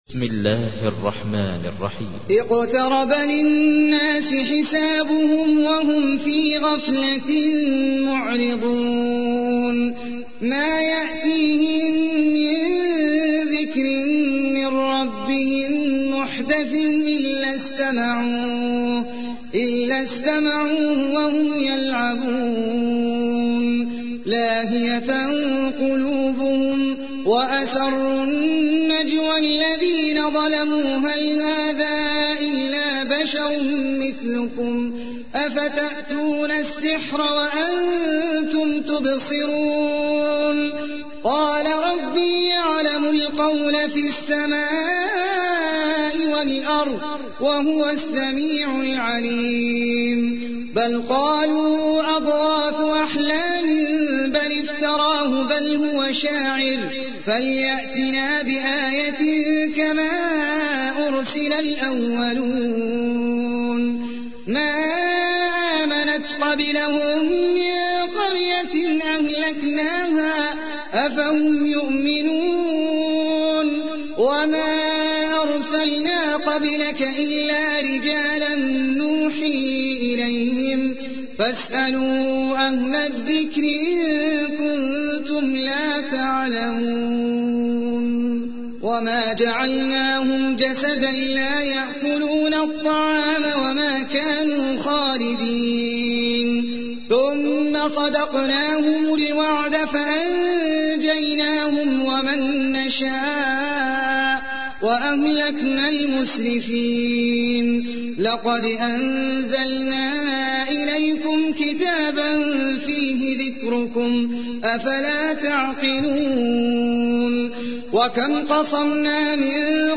تحميل : 21. سورة الأنبياء / القارئ احمد العجمي / القرآن الكريم / موقع يا حسين